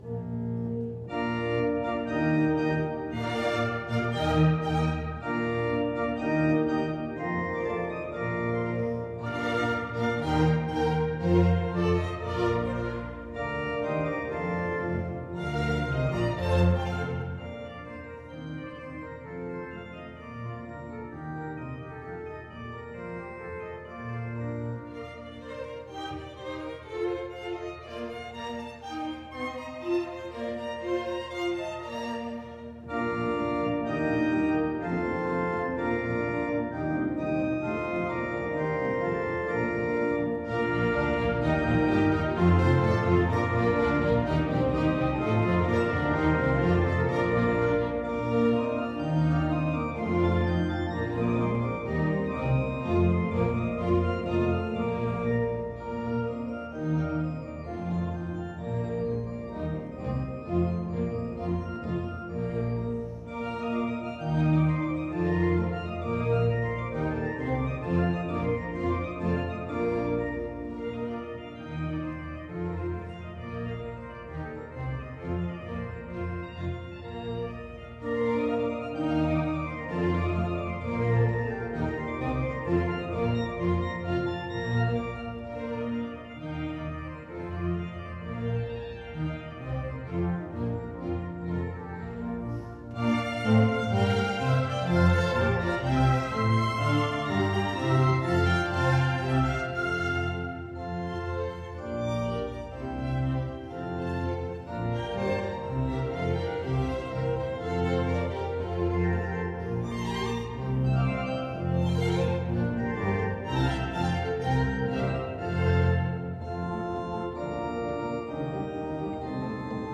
Classical
Organ